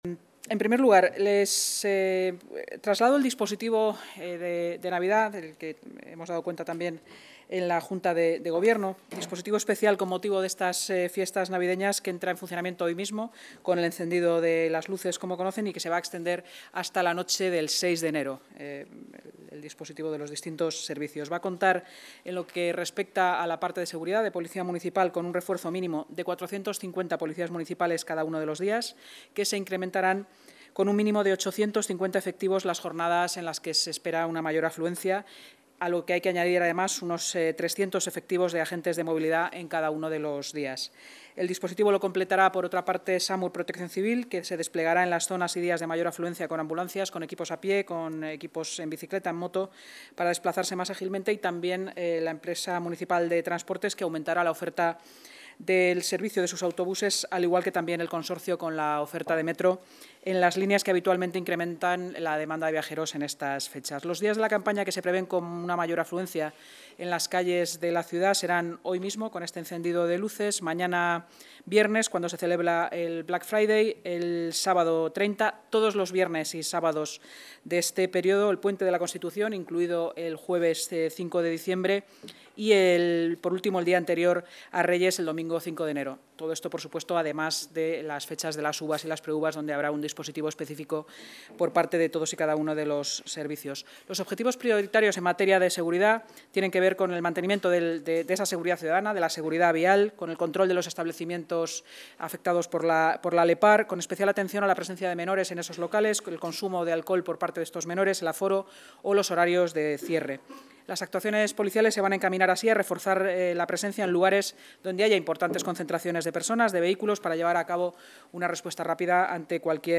Nueva ventana:La vicealcaldesa, delegada de Seguridad y Emergencias y portavoz municipal, Inma Sanz, durante la rueda de prensa